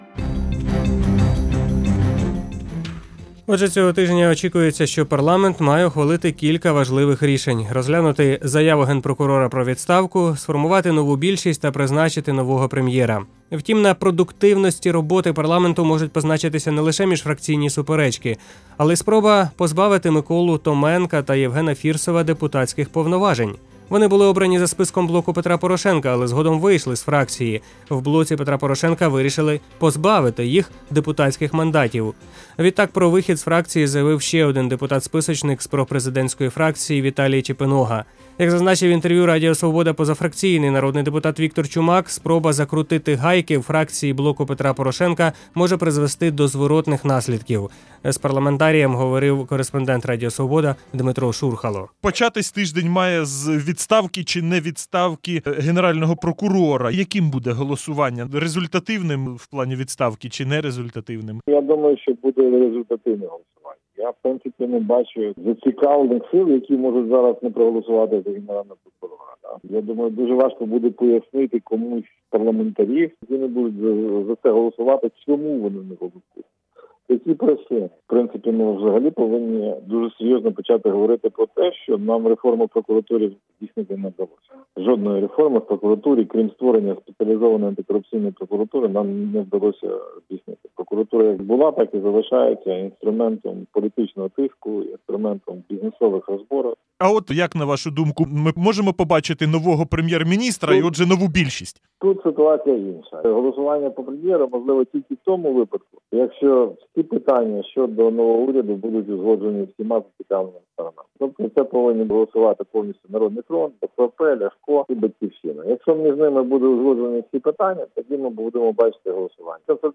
Як зазначив в інтерв’ю Радіо Свобода позафракційний народний депутат Віктор Чумак, спроба закрутити гайки у фракції «Блоку Петра Порошенка» може призвести до зворотних наслідків.